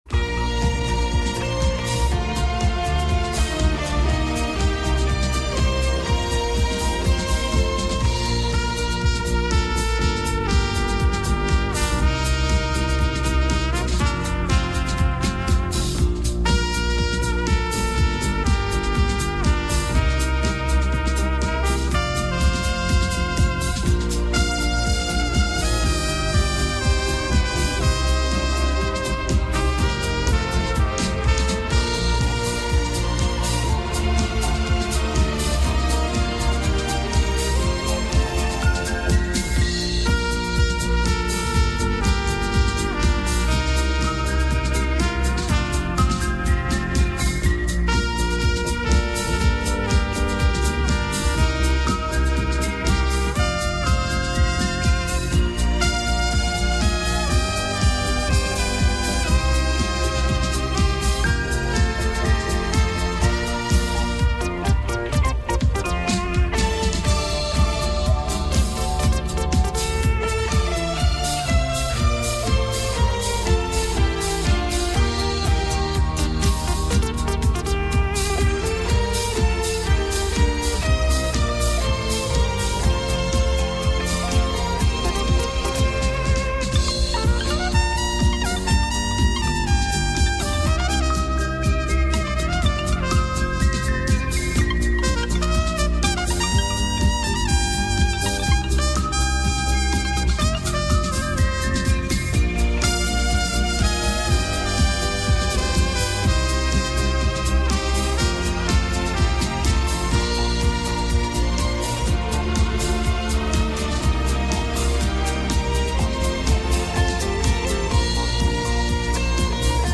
Несколько старых записей с приёмника. Качество плоховатое.